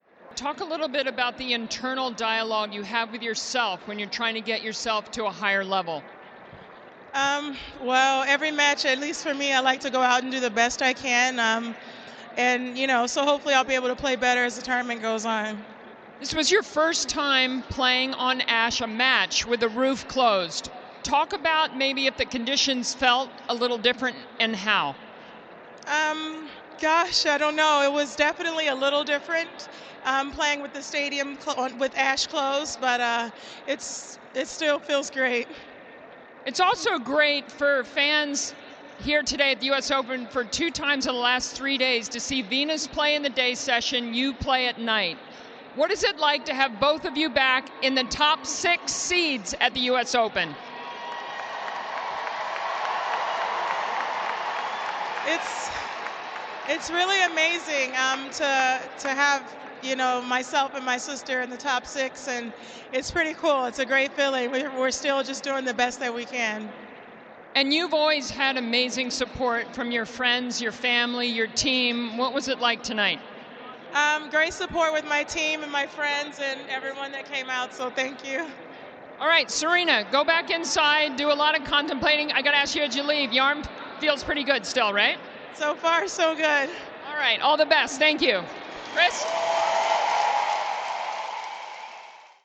Serena's 2nd-round post match interview